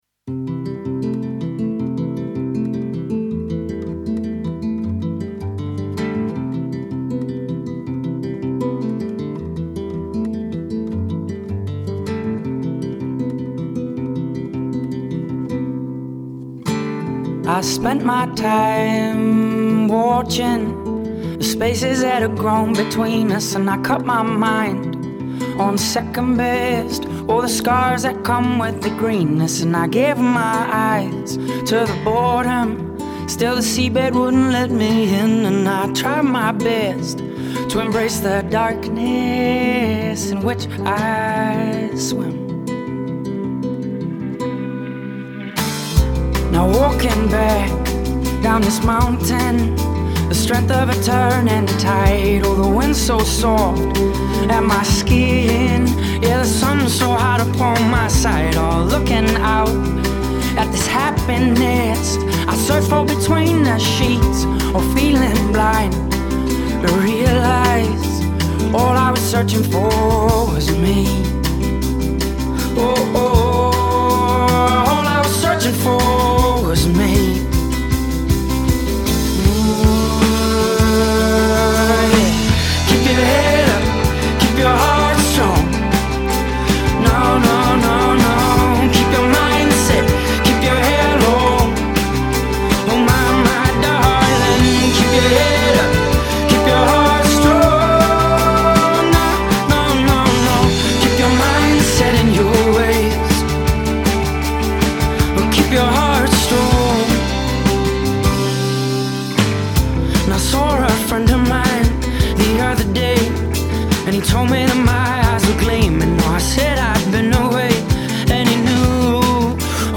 Its schmaltzy… but whatever, I like the positive vibe.